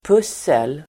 Uttal: [p'us:el]